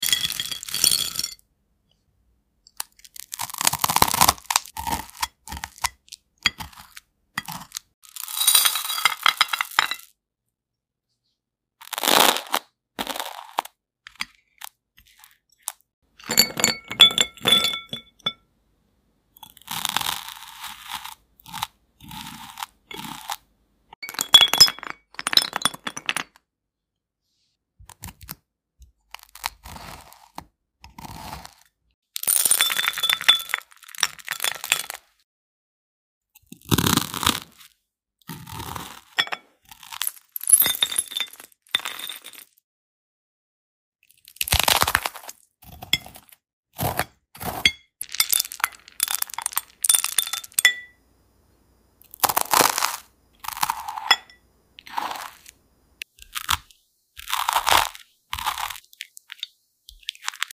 Can You Hear That Glass‑fruit Sound Effects Free Download